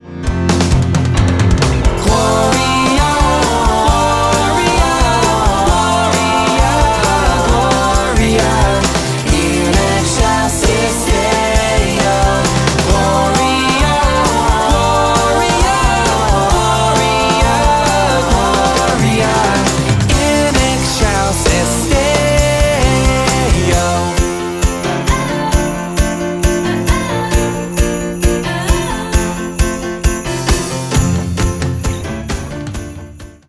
recorded vocals, edited, mixed, mastered